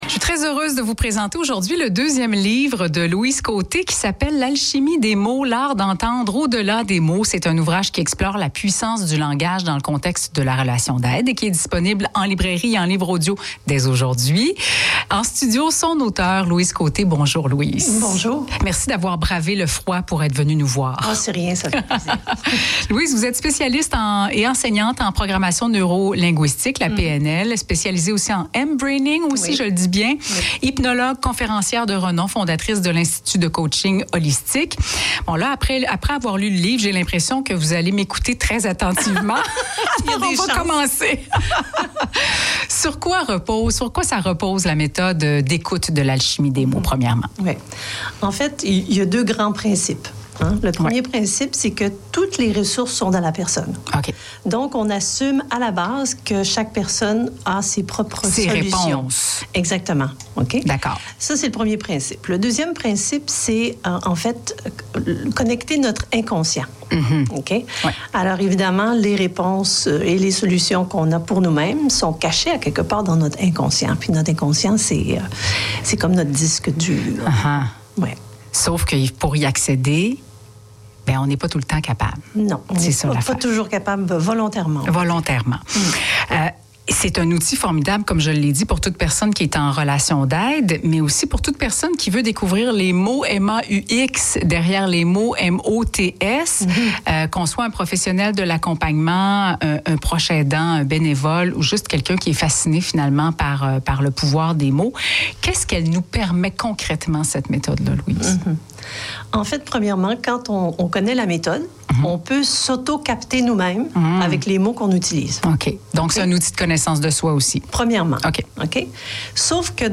Les entrevues du FM 103,3